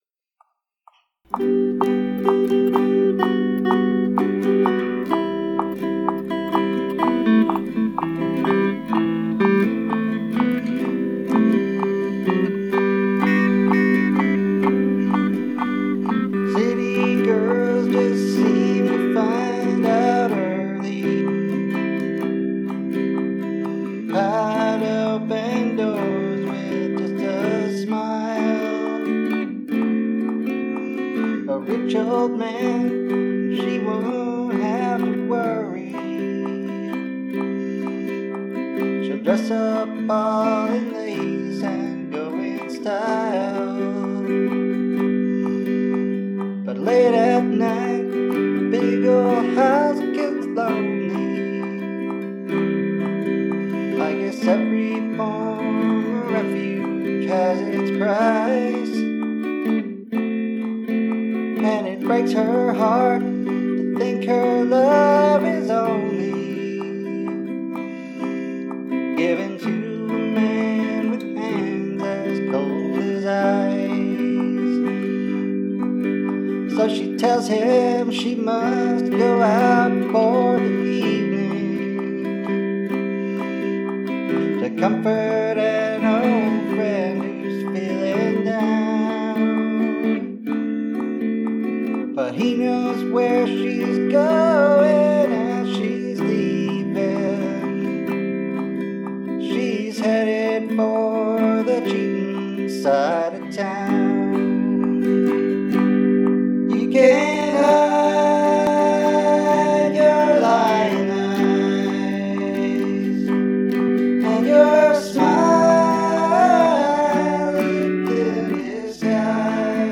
Tag: Guitar